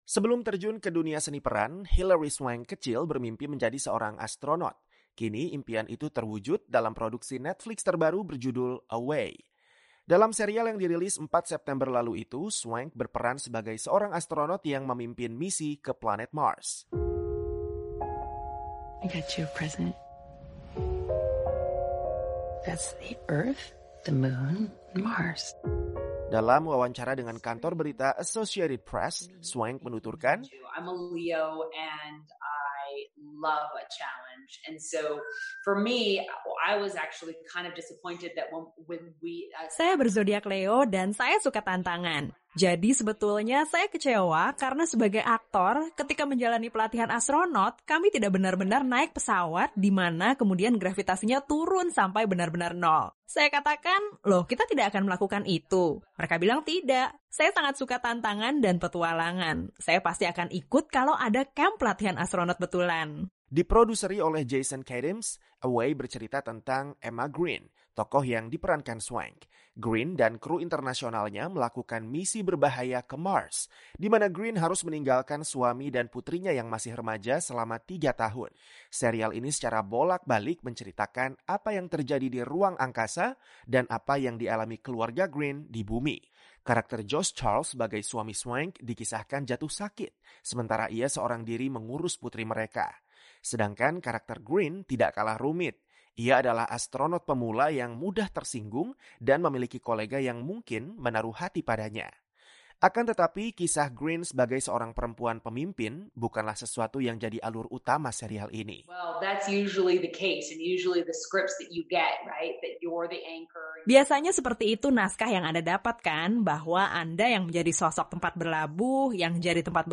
Aktris peraih dua piala Oscar, Hilary Swank, membintangi serial fiksi ilmiah terbaru Netflix berjudul “Away”. Dalam wawancara dengan kantor berita Associated Press, Swank berbagi pengalamannya syuting untuk film seri yang mengisahkan misi berbahaya ke Mars dan drama keluarga di planet Bumi.